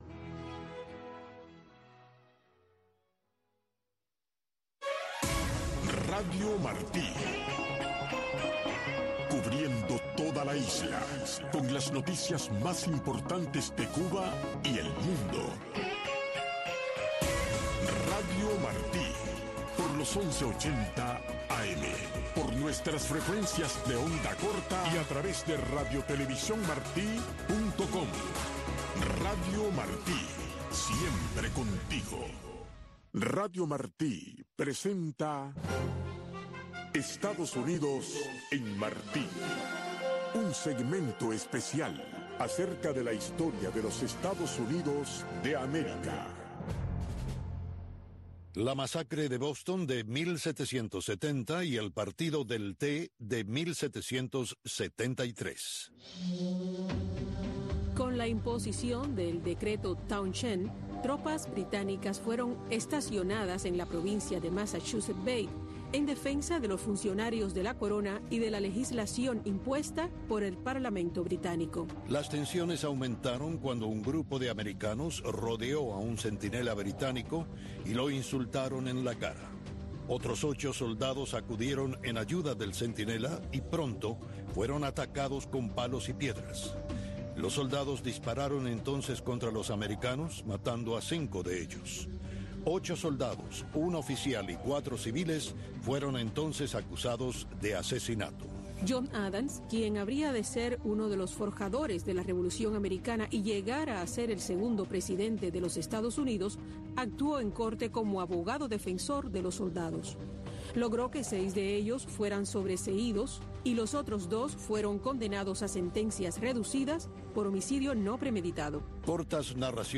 Radio Martí ha reunido a científicos de primer nivel de diferentes partes del mundo: astrónomos de la NASA, biólogos, doctores en Meteorología, expertos en Biodiversidad, entre otros